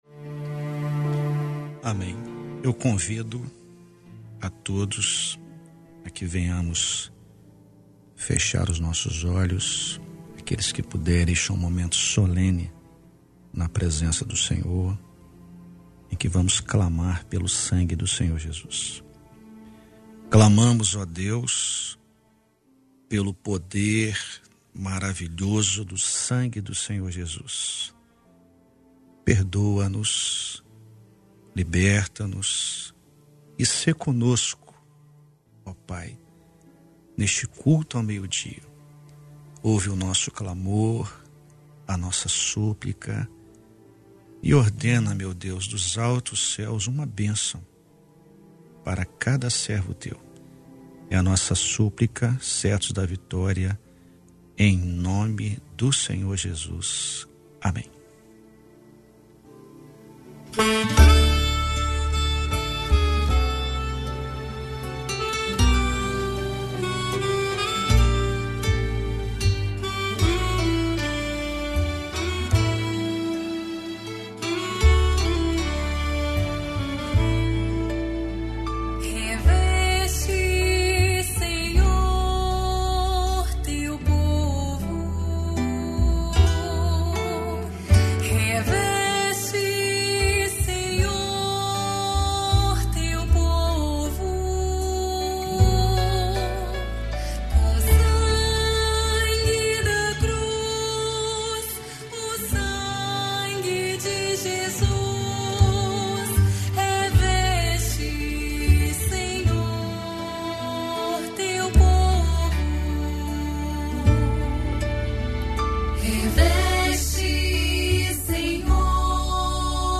Culto ao Meio Dia transmitido em 17/11/2020 - Rádio Maanaim
Culto de oração da Igreja Cristã Maranata.